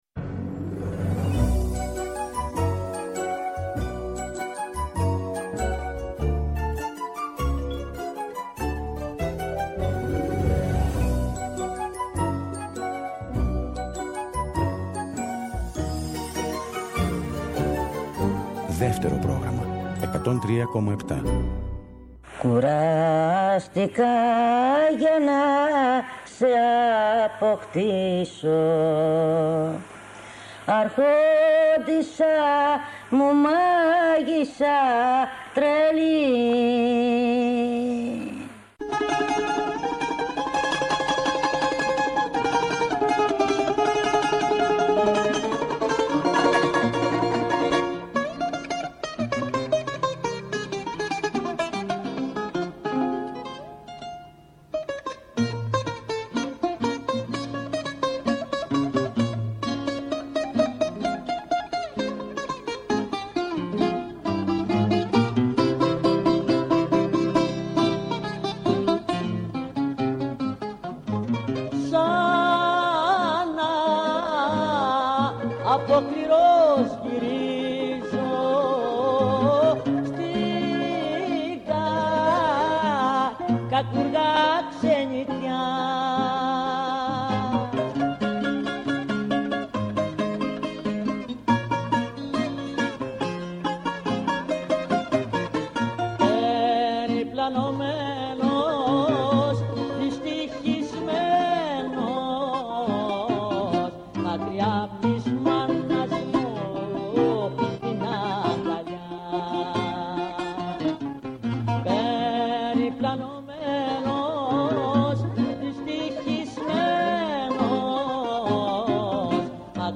ακούμε αποσπάσματα από συνεντεύξεις της Σωτηρίας Μπέλλου